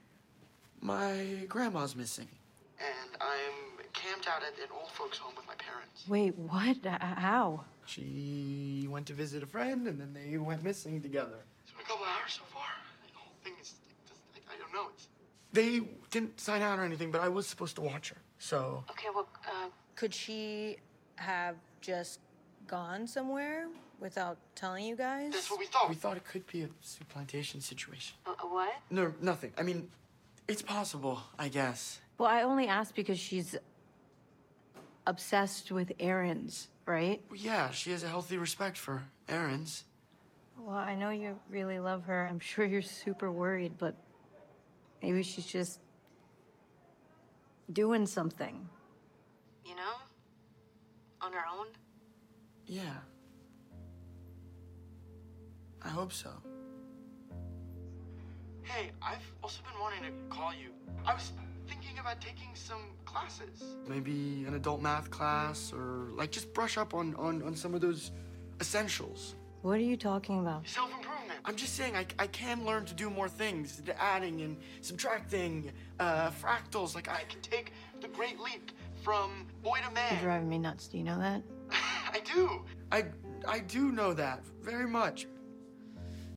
The scene shows Daniel (played by Fred Hechinger) in an almost nervous break down pledging to learn more math.